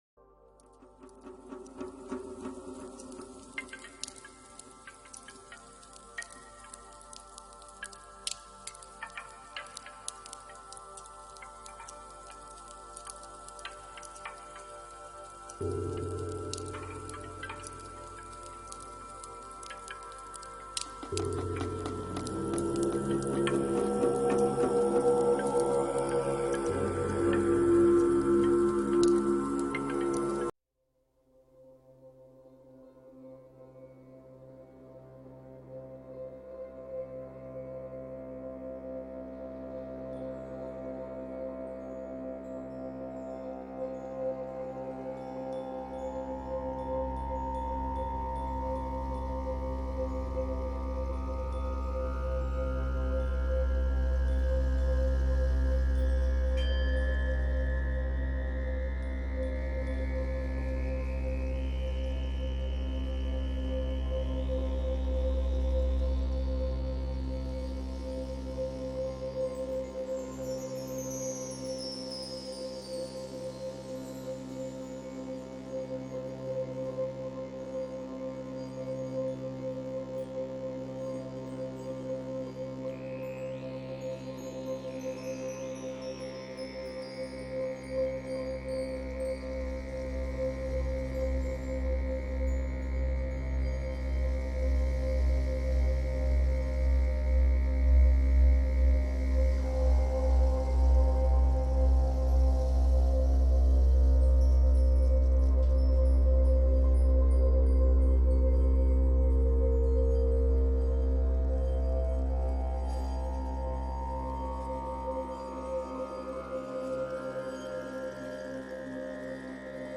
Chant sacré Tibétain
CHANTS TIBÉTAINS
Chant-sacres-du-Tibet-extremement-puissant-pour-la-meditation.mp3